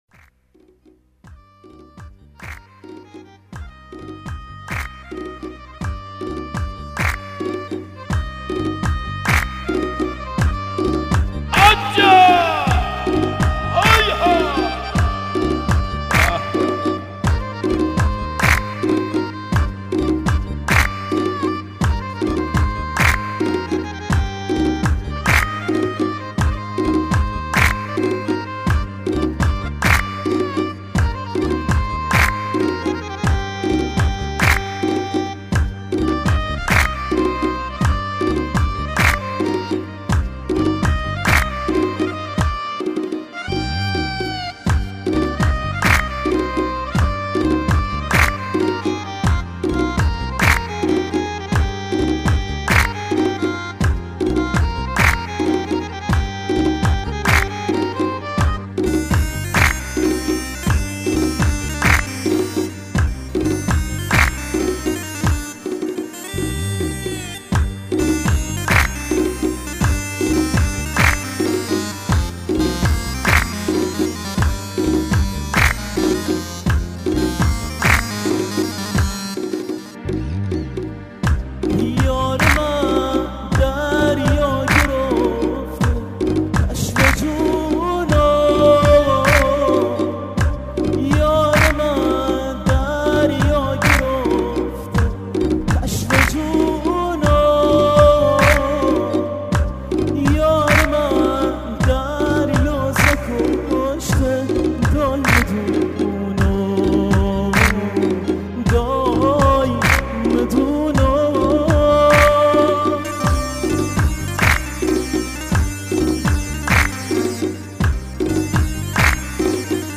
اهنگ لری بویر احمدی ممسنی